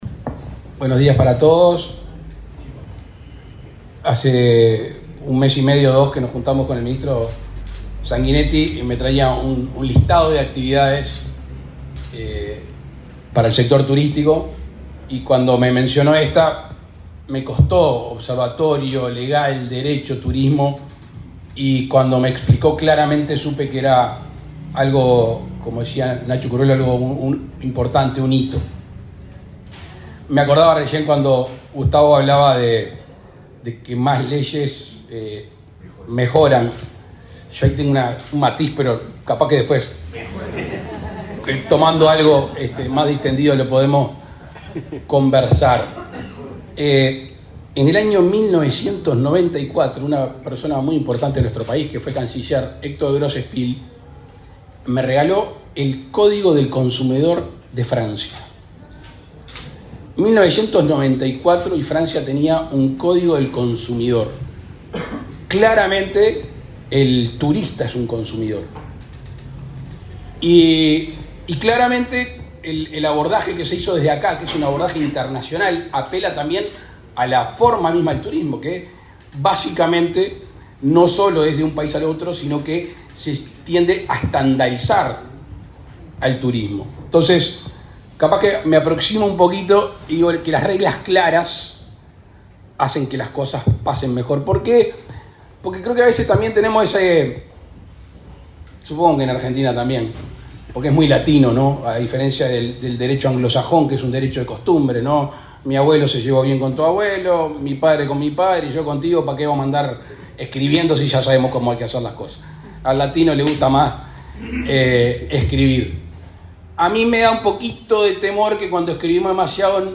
Palabras del presidente Luis Lacalle Pou
El presidente de la República, Luis Lacalle Pou, participó, este martes 3 en Montevideo, en la inauguración del Observatorio de Derecho del Turismo de